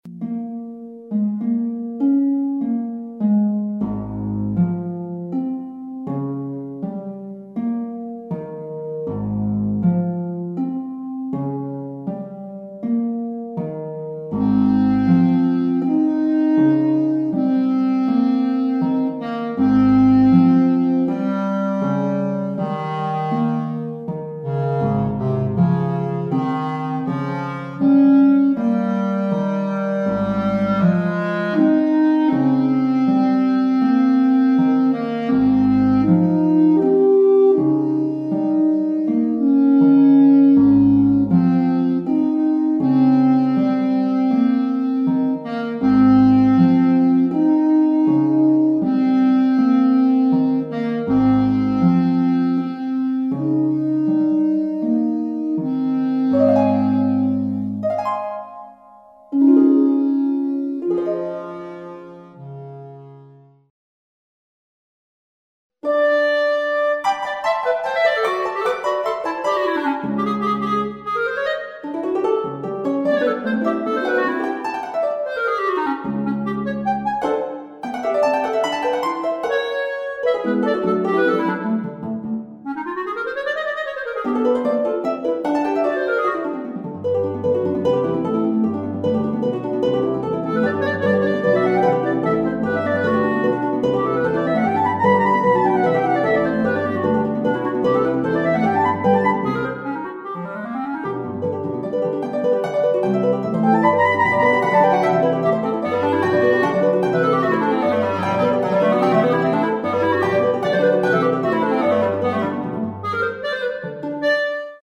Five character pieces for clarinet/bass clarinet and harp.